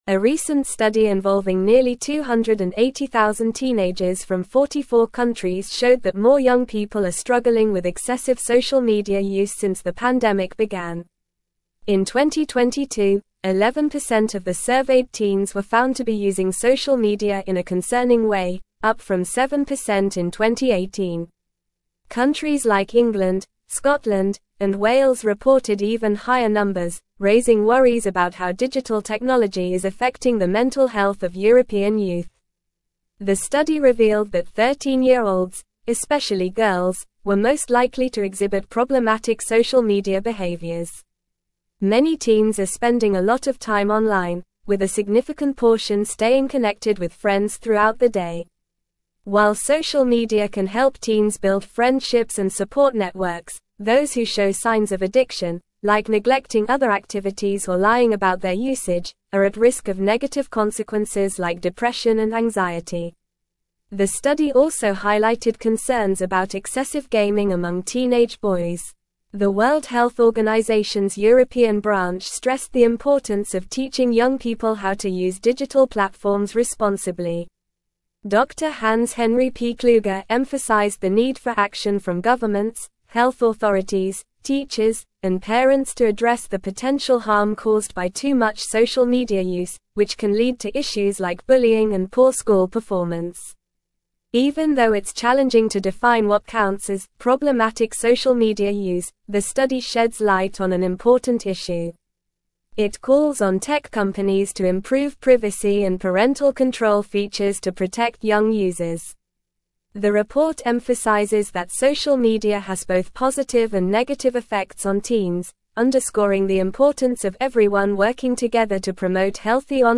Normal
English-Newsroom-Upper-Intermediate-NORMAL-Reading-Concerning-Increase-in-Teenage-Social-Media-Use-Detected.mp3